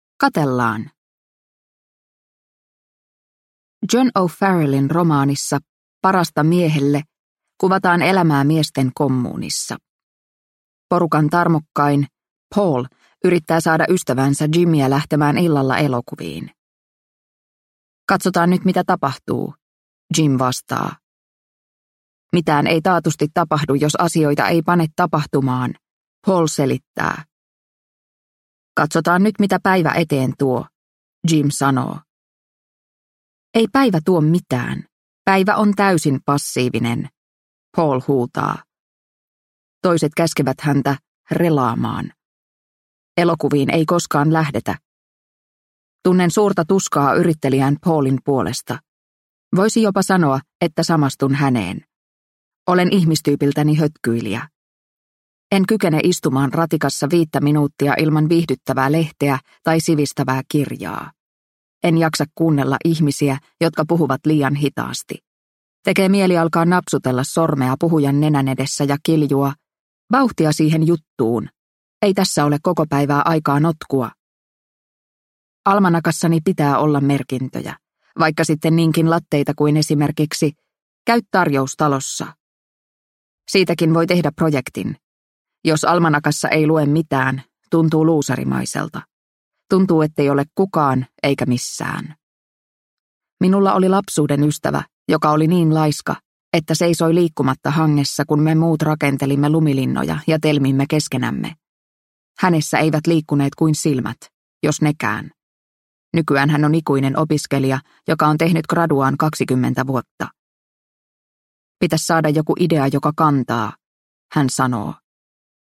Palele porvari – Ljudbok – Laddas ner